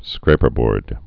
(skrāpər-bôrd)